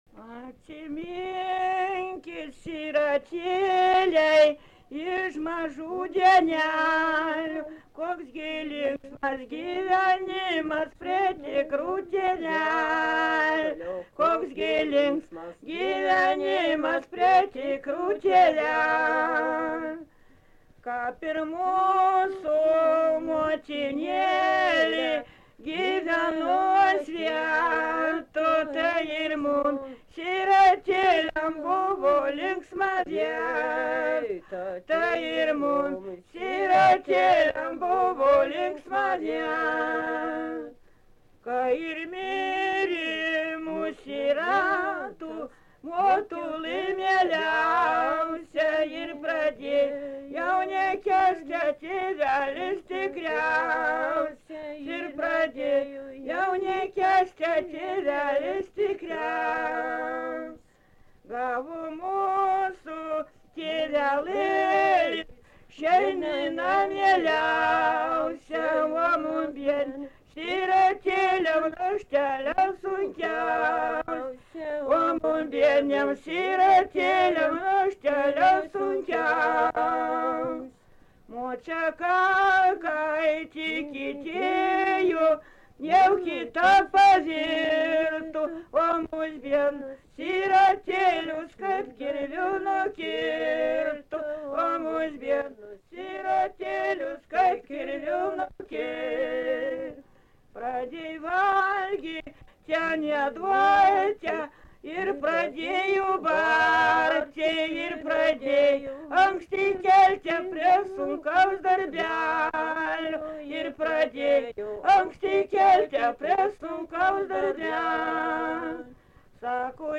Dalykas, tema daina
Erdvinė aprėptis Stakliškės
Atlikimo pubūdis vokalinis